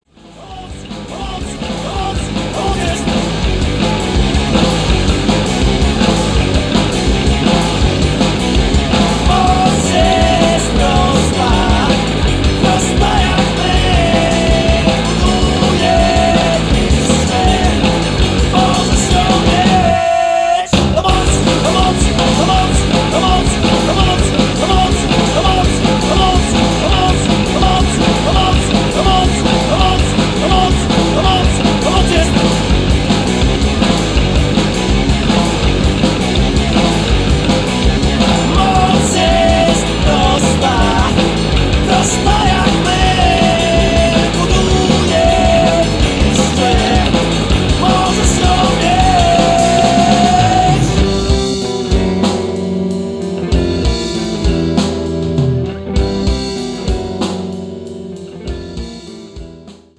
wokal, gitara
perkusja